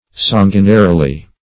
sanguinarily - definition of sanguinarily - synonyms, pronunciation, spelling from Free Dictionary Search Result for " sanguinarily" : The Collaborative International Dictionary of English v.0.48: Sanguinarily \San"gui*na*ri*ly\, adv.
sanguinarily.mp3